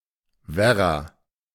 The Werra (German pronunciation: [ˈvɛʁa]
De-Werra.ogg.mp3